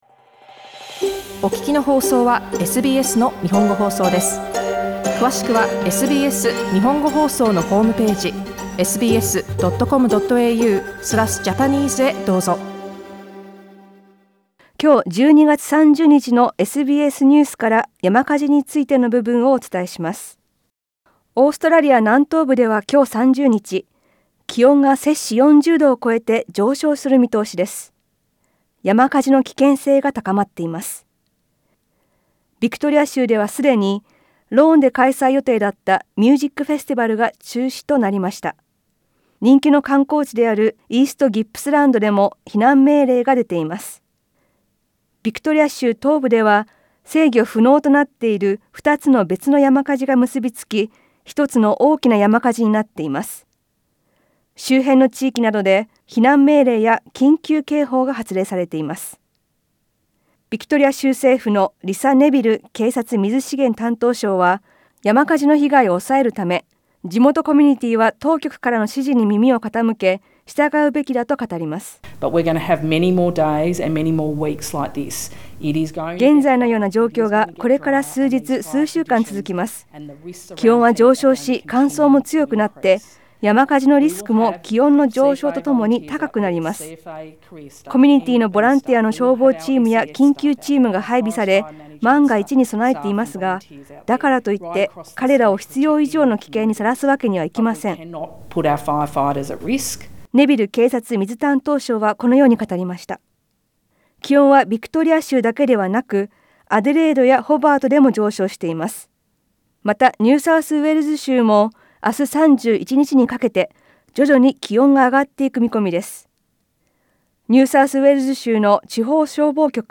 SKIP ADVERTISEMENT ＊詳しい音声リポートは写真をクリックしてどうぞ。